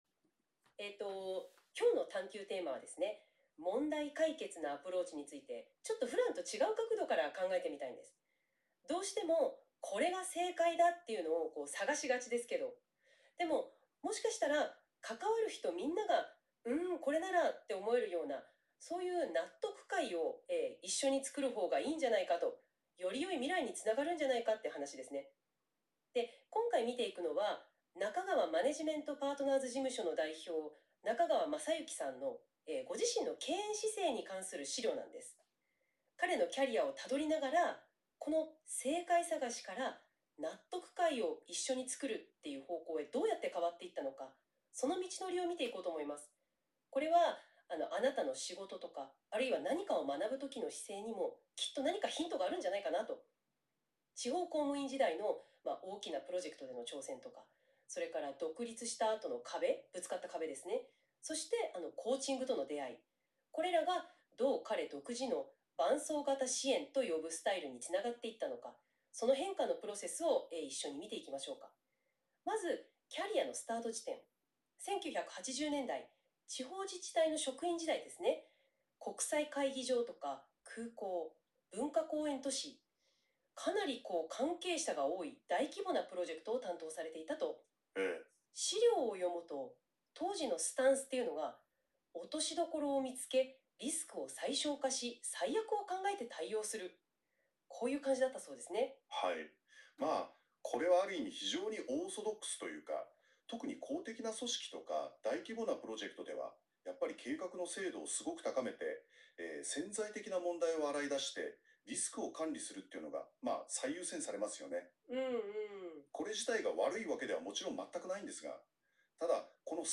中川マネジメントパートナー事務所の経営姿勢にについてお二人のコーディネーターに語り合っていただきました。